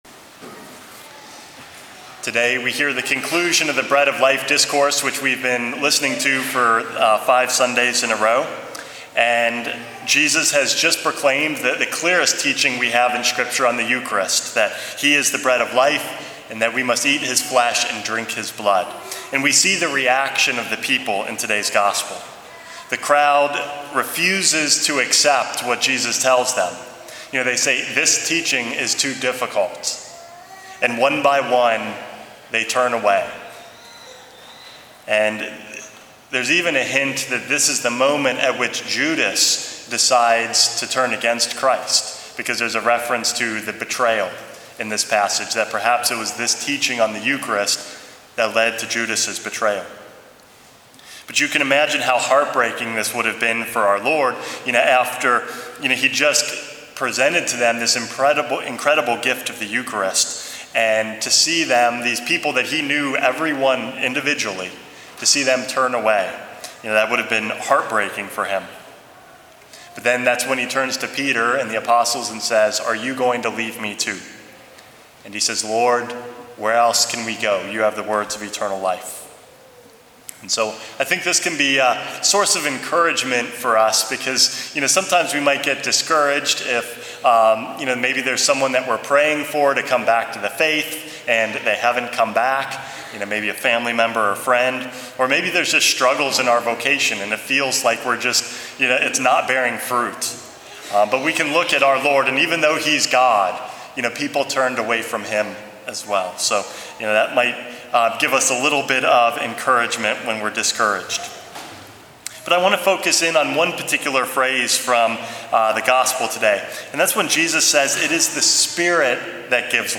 Homily #411 - Spirit and Life